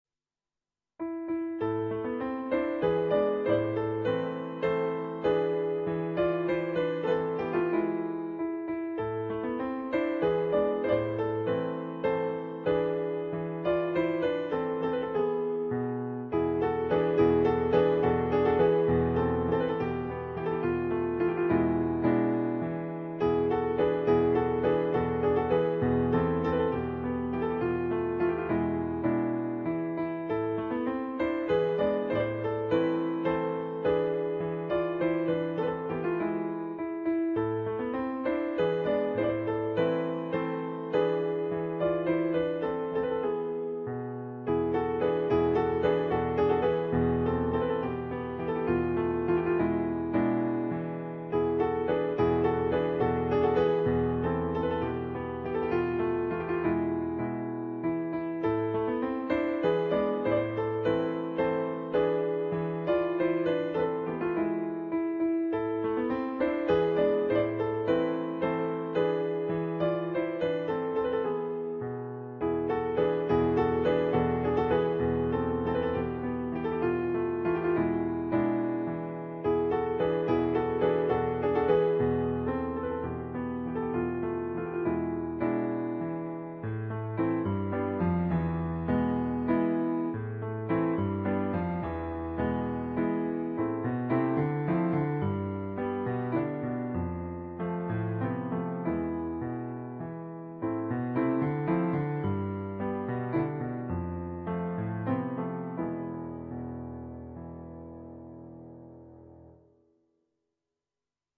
for Solo Piano
on Yamaha digital pianos.